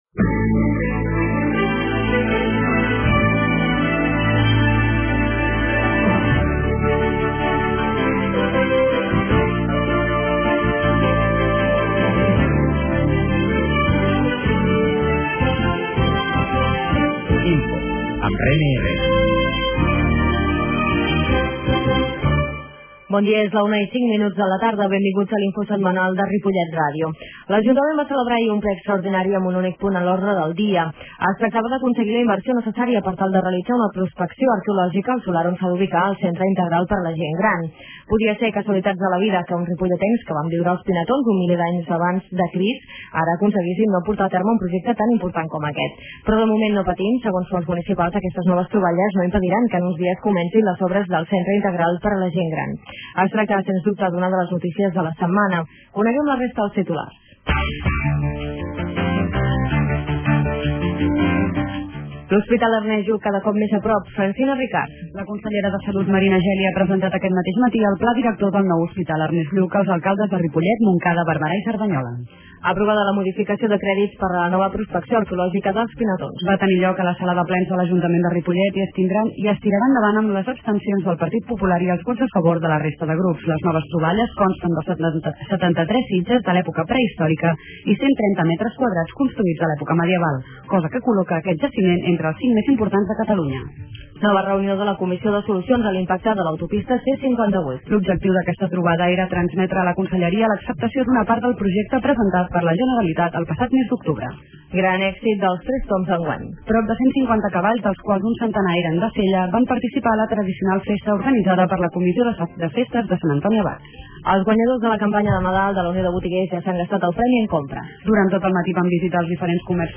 Comunicació INFO de la setmana: 25 de gener de 2008 -Comunicació- 25/01/2008 Escolteu en directe per la r�dio o la xarxa el resum de not�cies de Ripollet R�dio (91.3 FM), que s'emet en directe a les 13 hores.
La qualitat de so ha estat redu�da per tal d'agilitzar la seva desc�rrega.